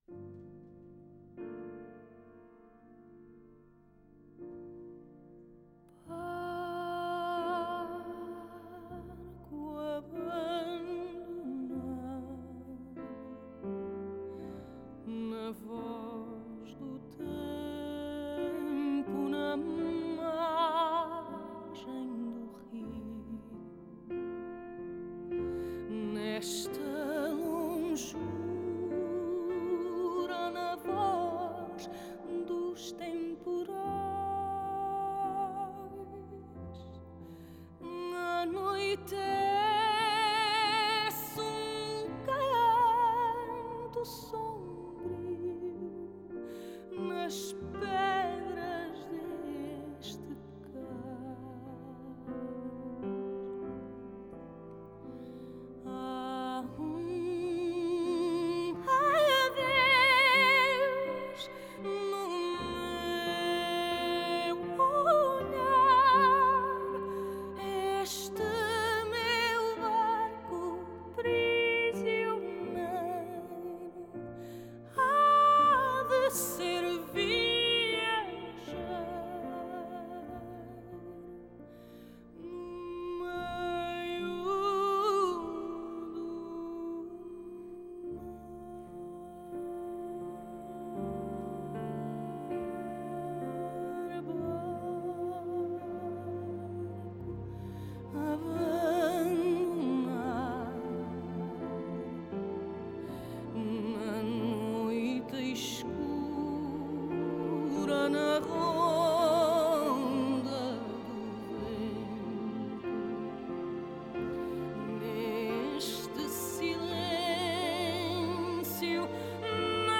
Genre: Score
DSD-layer: Stereo, 5.1 Multichannel.